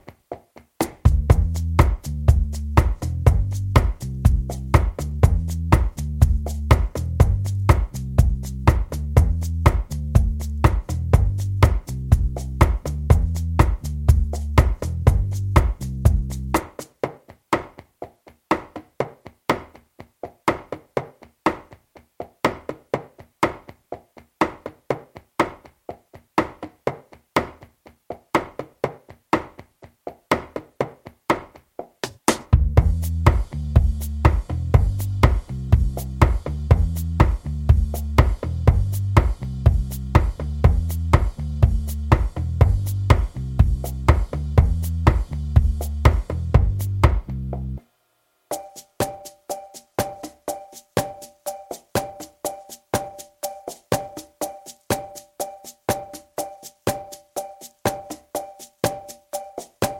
Minus Main Guitar For Guitarists 3:21 Buy £1.50